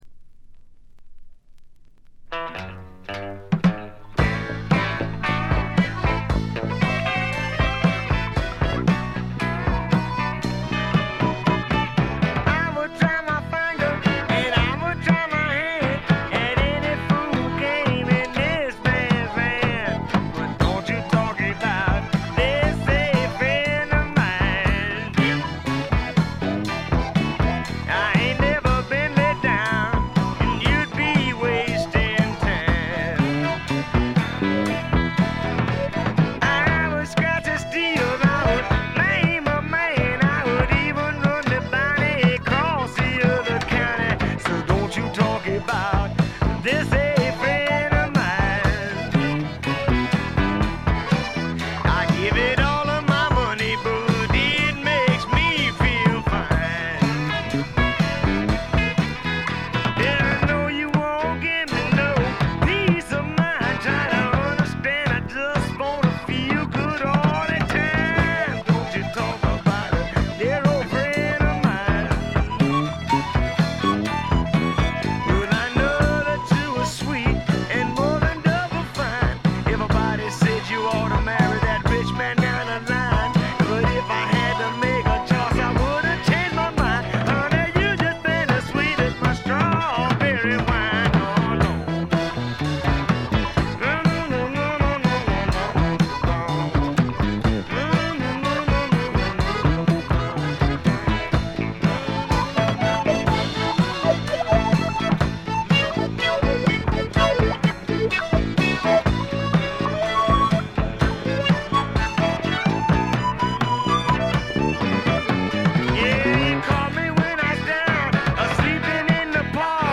ディスク：部分試聴ですがほとんどノイズ感無し。
試聴曲は現品からの取り込み音源です。